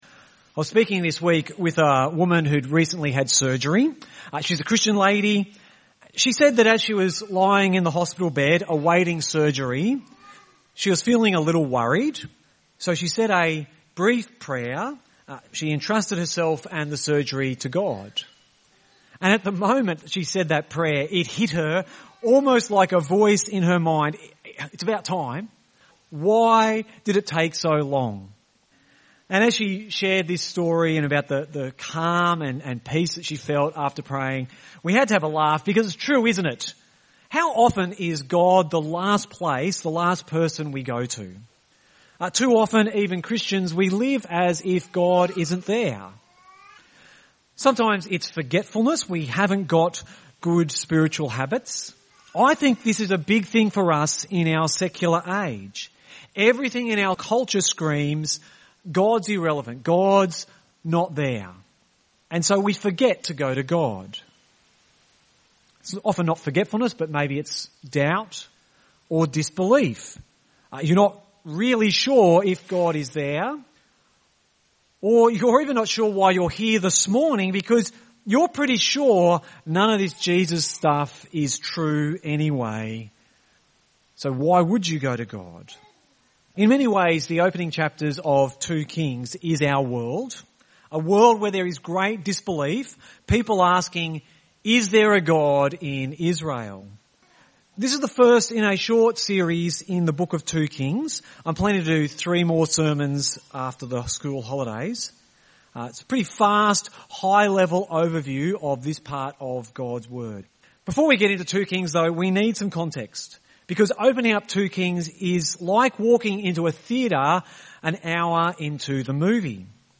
Weekly sermon podcast from Gympie Presbyterian Church